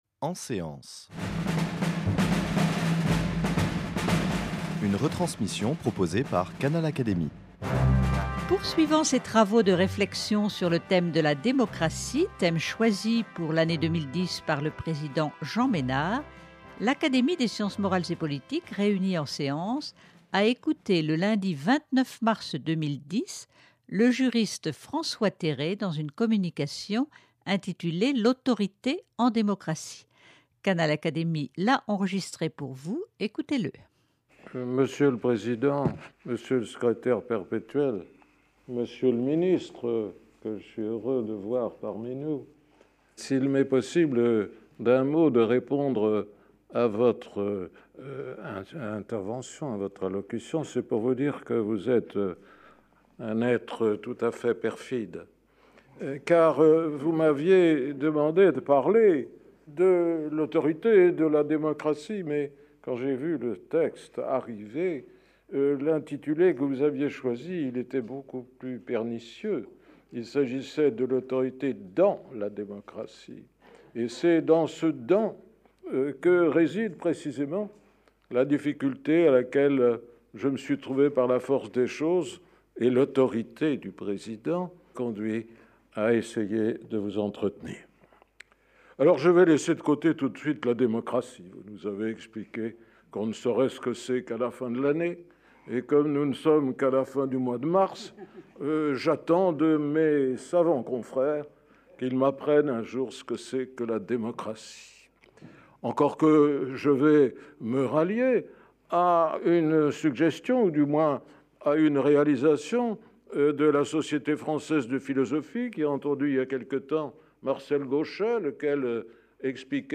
Le concept connaît un recul que le juriste François Terré, membre de l’Académie des sciences morales et politiques, a analysé devant ses confrères réunis en séance. Quelles nuances entre autorité et pouvoir ? Pourquoi ce recul de l’autorité et quels en sont les effets sur notre démocratie ?